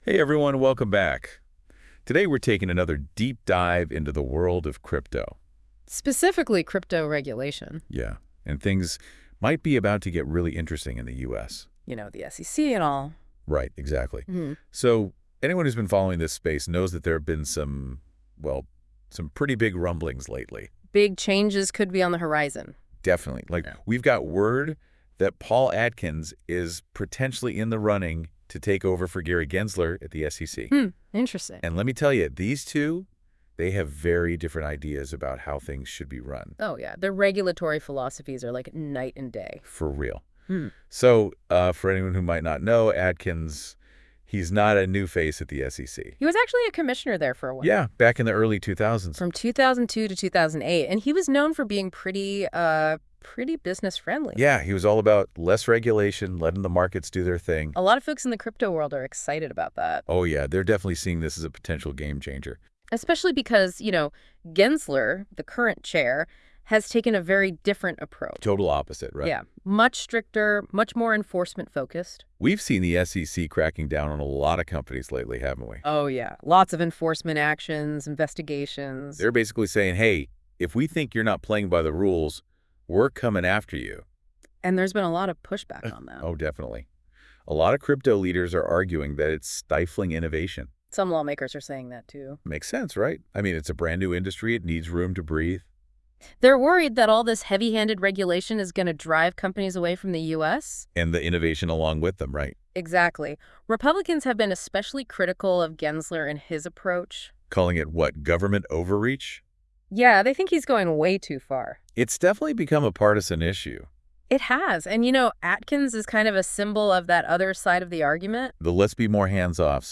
Podcast Discussion: Deep Dive Into This Article.